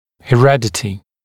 [he’redətɪ] [hɪ-][хэ’рэдэти] [хи-]наследственность